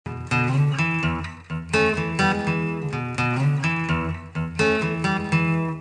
blues
blues.mp3